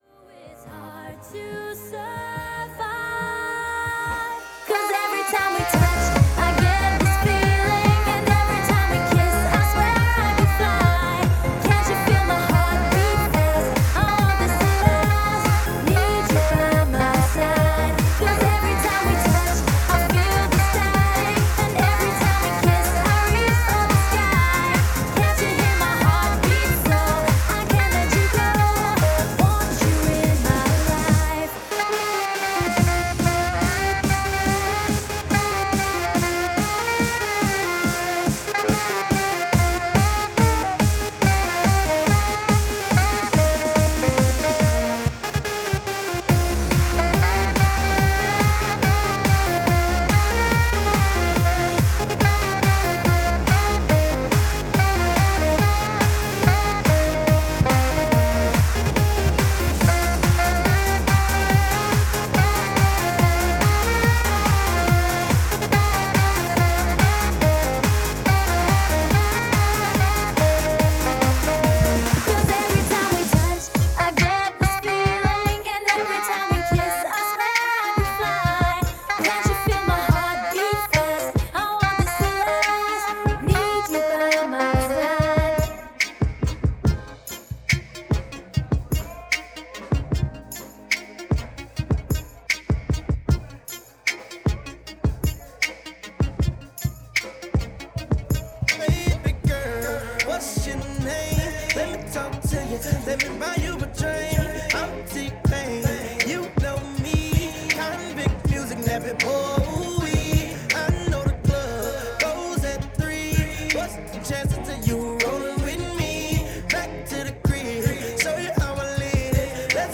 A live wedding mix